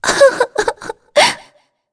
Shea-Vox_Sad.wav